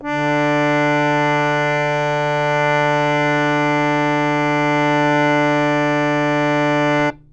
samples / harmonium / D3.wav